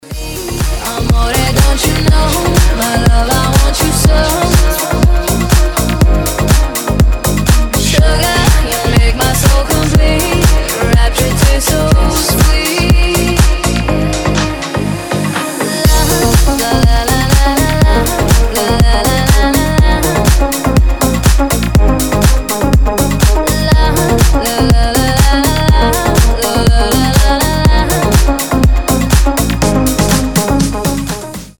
deep house
Club House
красивый женский голос